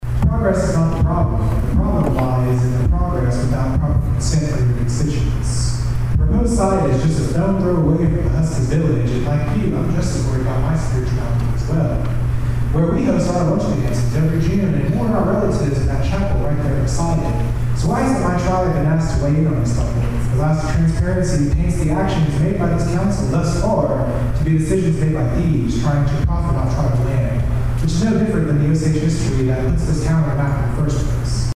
At Tuesday's evening's city council meeting in Pawhuska, council members had the option to provide a letter to Buckley Bros. Holdings, LLC showing they wanted to move forward with the data center.